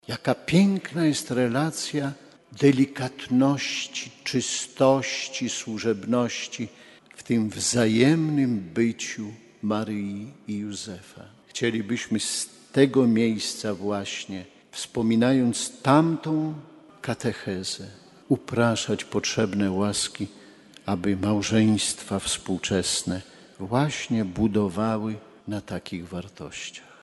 Centralnym Puntem była uroczysta msza św., której przewodniczył bp Romuald Kamiński.